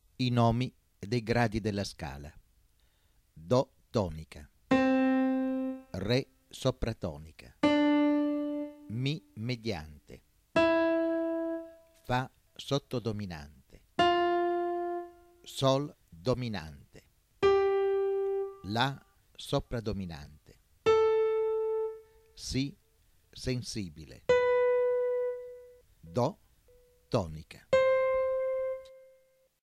03.  Ascolto della scala di Do Maggiore in cui si mette in evidenza il nome di ogni grado.